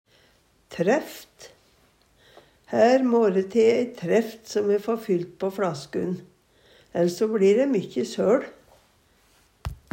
træft - Numedalsmål (en-US)